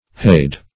hade - definition of hade - synonyms, pronunciation, spelling from Free Dictionary
Hade \Hade\ (h[=a]d), n. [Cf. AS. heald inclined, bowed down, G.